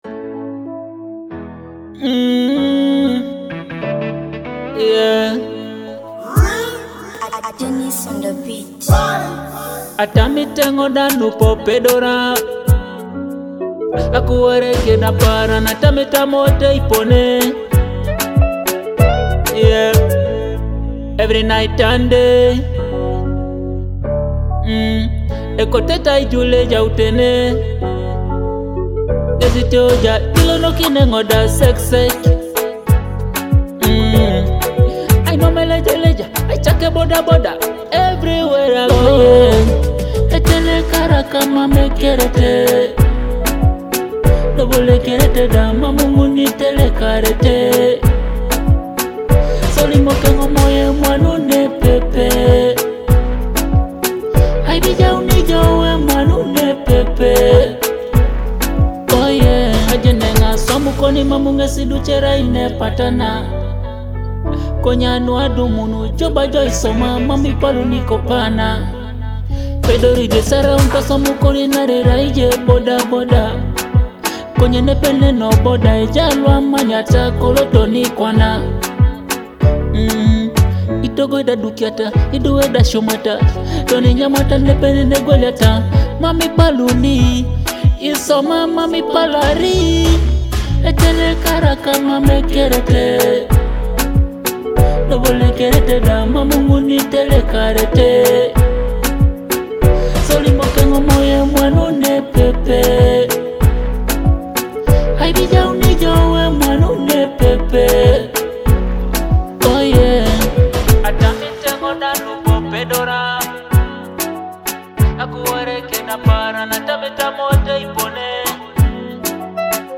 an inspiring Teso track about working hard with purpose.
inspiring anthem